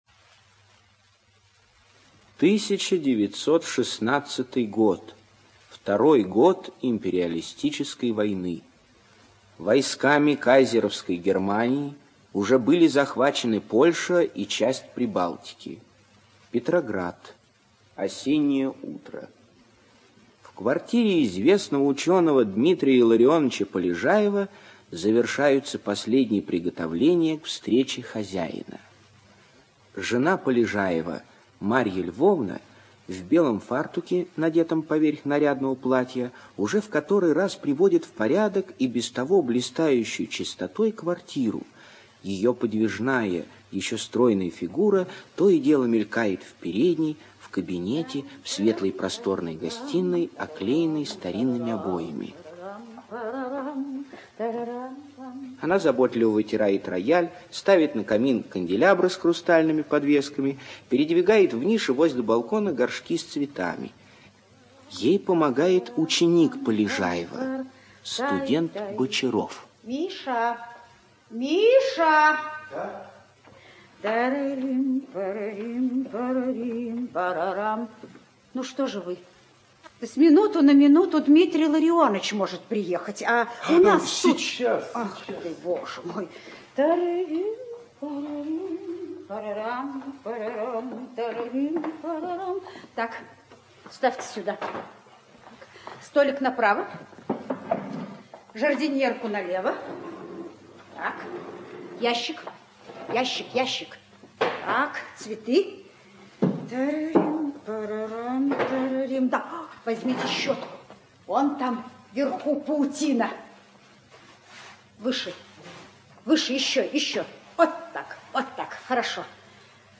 ЧитаютКольцов Ю., Трошин В., Андровская О., Богомолов В., Топчиев Л.
ЖанрРадиоспектакли